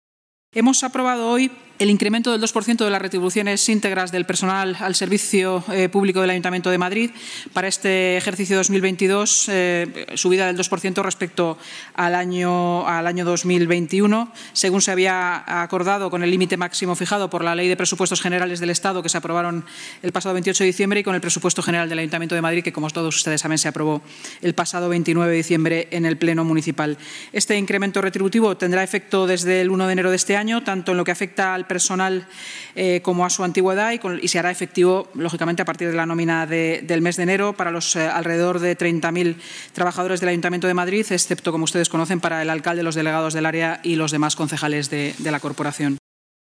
Sanz en la rueda de prensa posterior a la Junta de Gobierno